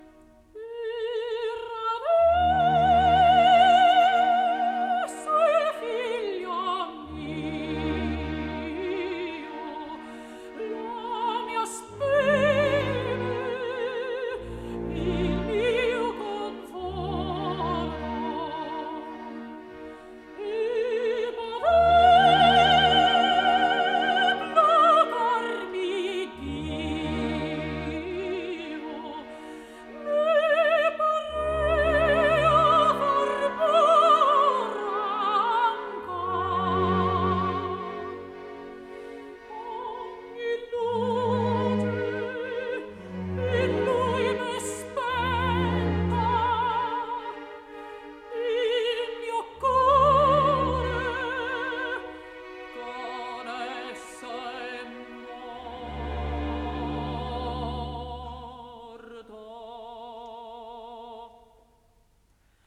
Lucrezia’s aria
soprano
1979 Beulah Live recording